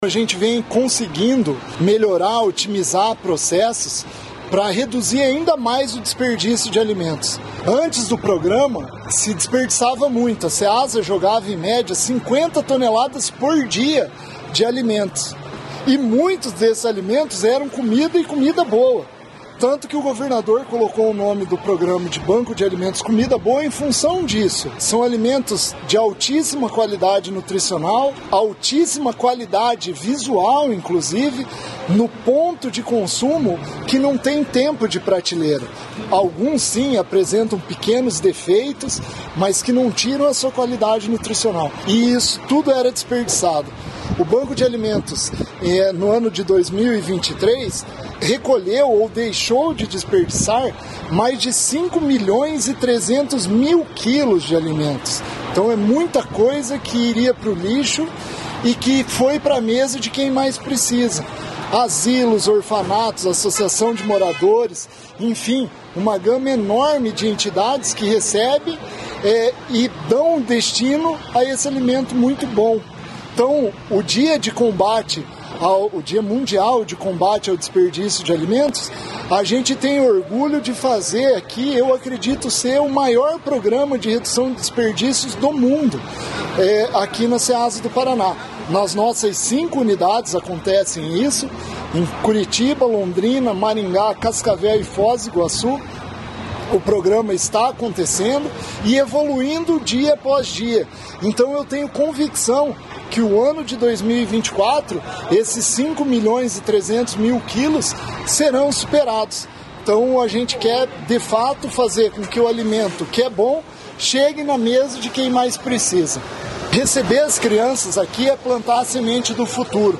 Sonora do diretor-presidente da Ceasa Paraná, Eder Bublitz, sobre o programa Banco de Alimentos Comida Boa